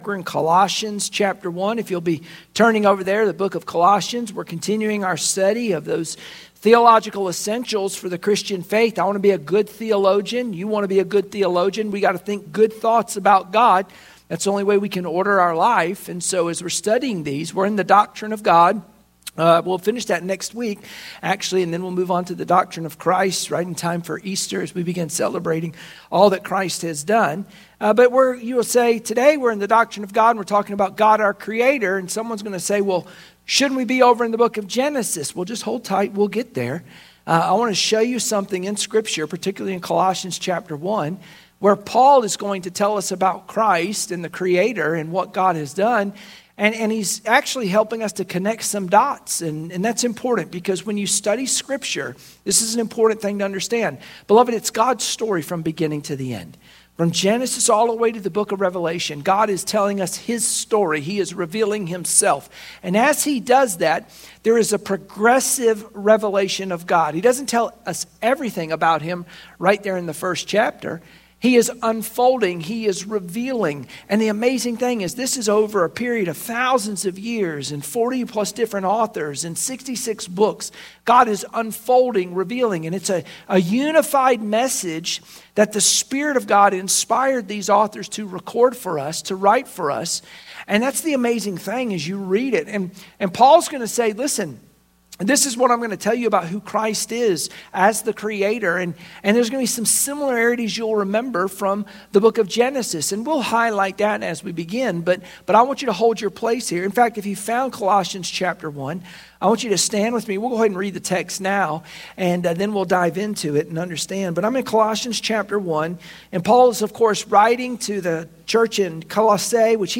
Sunday Morning Worship Passage: Colossians 1:15-17 Service Type: Sunday Morning Worship Share this